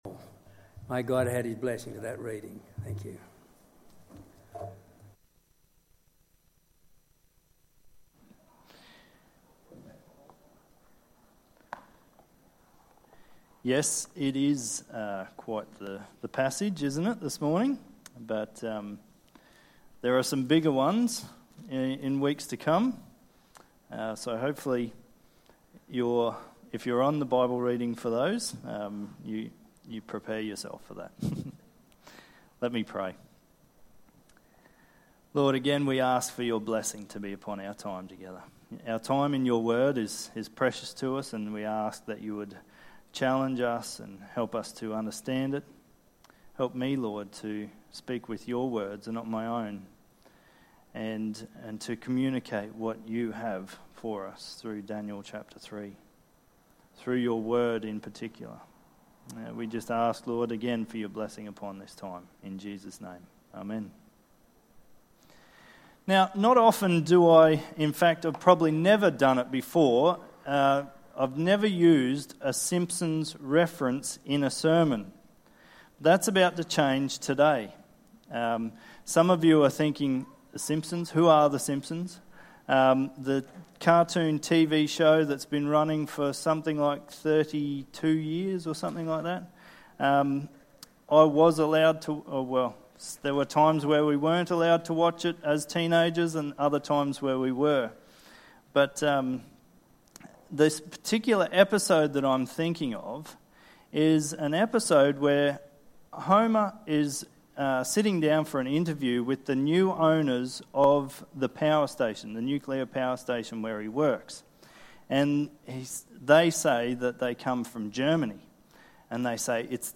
Sermons | Tenthill Baptist Church
27/03/2022 Sunday service